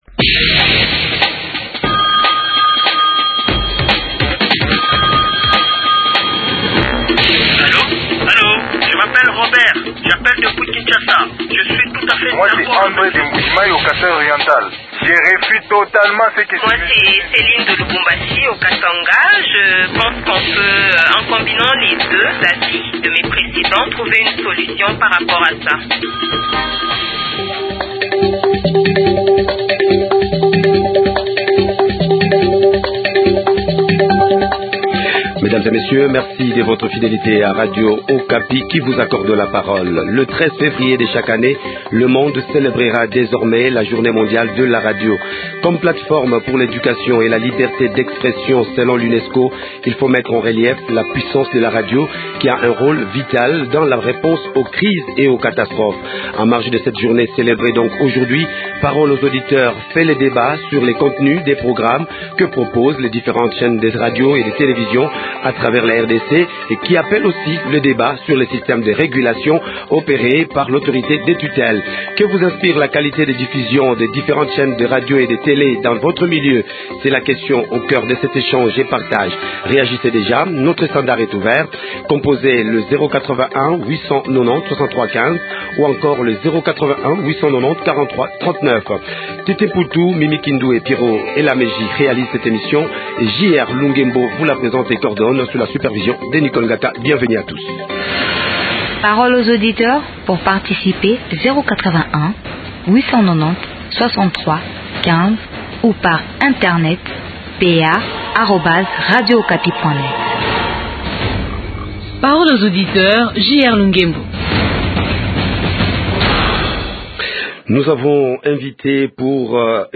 Le 13 février de chaque année le monde célèbrera désormais, la journée mondiale de la radio. A l’occasion de cette célébration, Parole aux auditeurs vous a permis de vous exprimer sur les contenus des programmes des différents médias de la RDC.